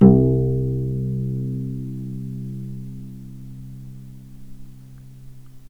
vc_pz-C2-mf.AIF